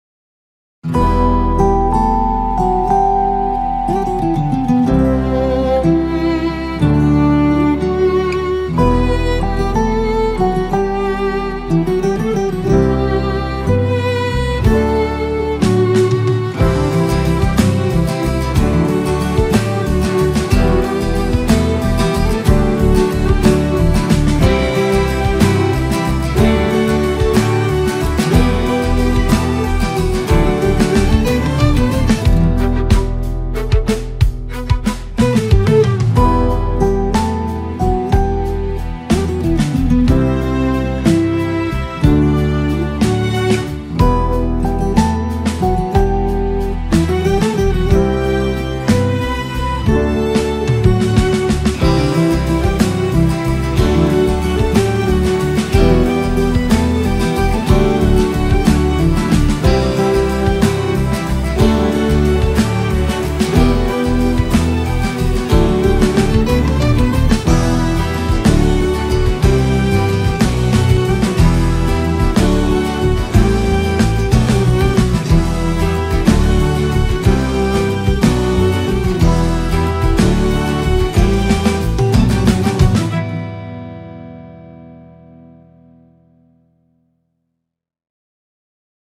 duygusal huzurlu rahatlatıcı fon müziği.